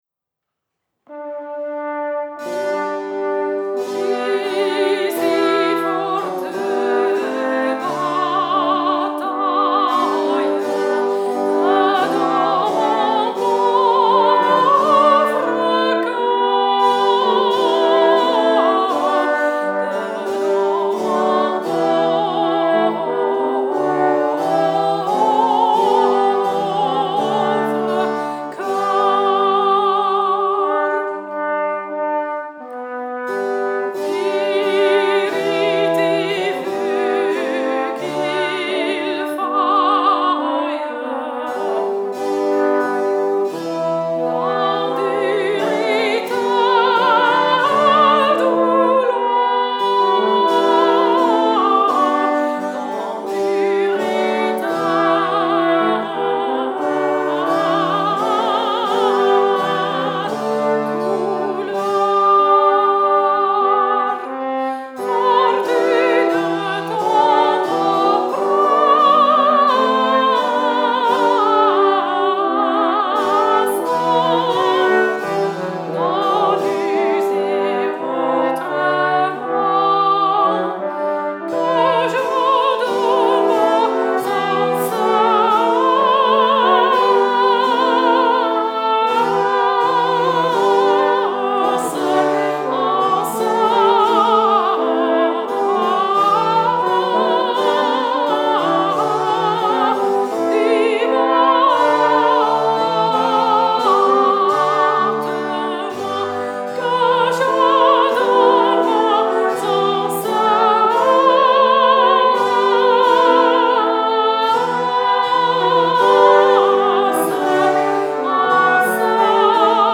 nella Chiesa di San Filippo Neri  (Savona).
Sono state utilizzate esclusivamente copie di strumenti rinascimentali.
Soprano
Cornetto
Bombarda
Trombone Contralto
Trombone Tenore
Flauto e Dulciana
Percussioni
Clavicembalo